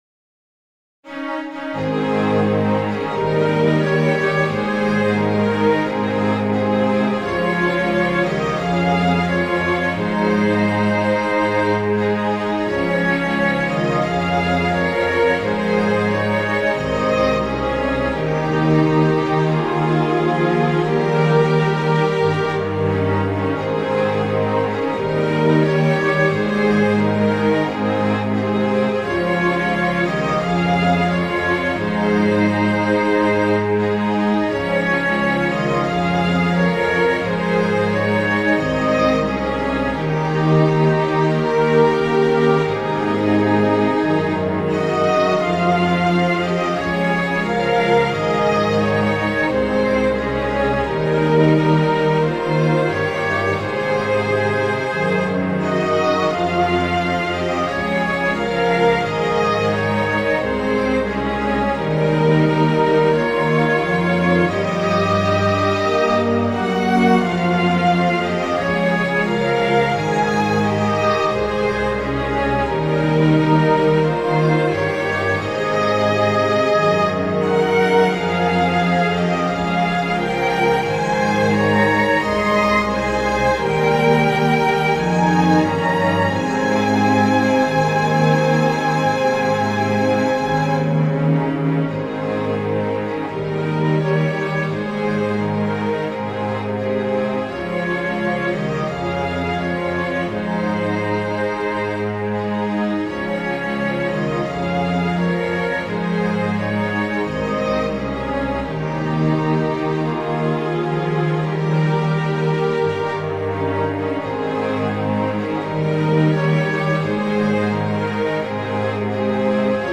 クラシックロング明るい穏やか